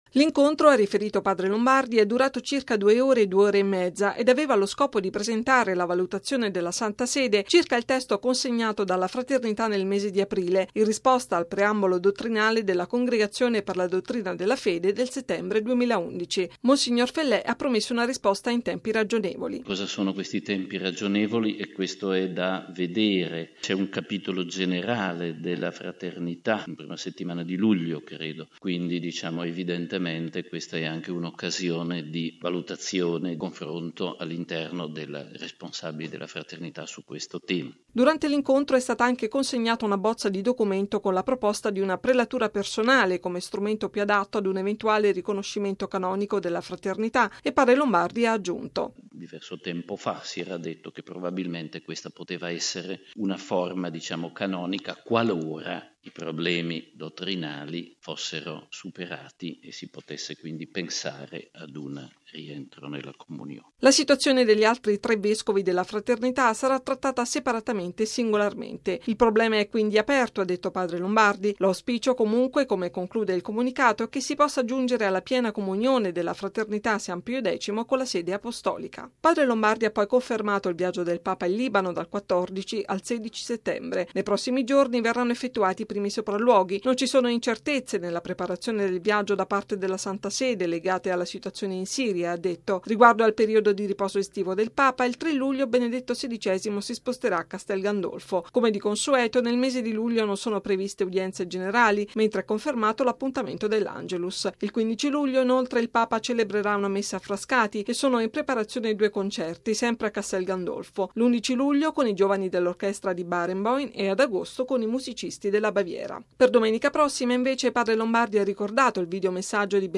Radiogiornale del 14/06/2012 - Radio Vaticana